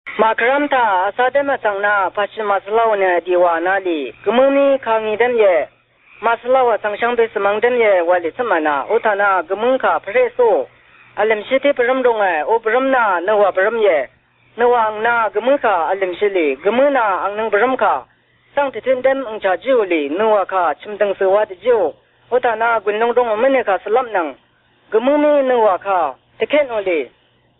It could also be from the Hmong-Mien family because the intonation pattern sort of reminds me of Hmong.